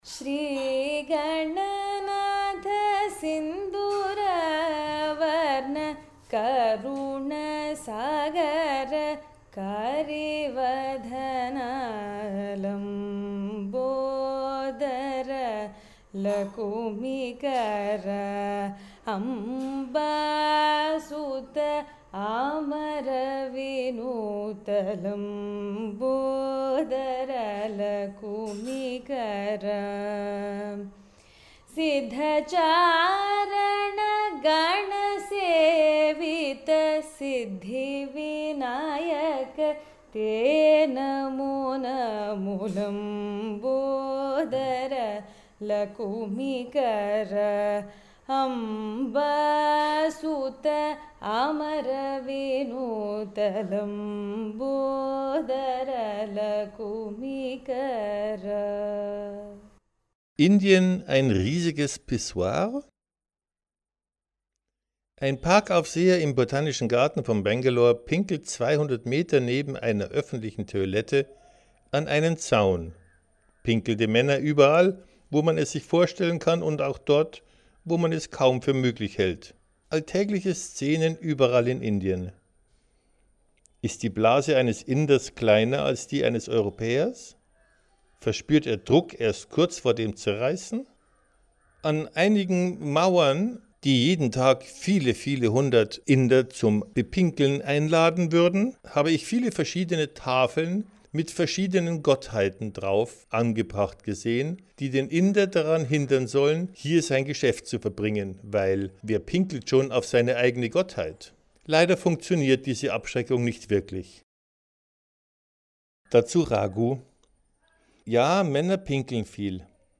Atmosphären der einzelnen Kapitel, hat es während seiner Reisen aufgenommen.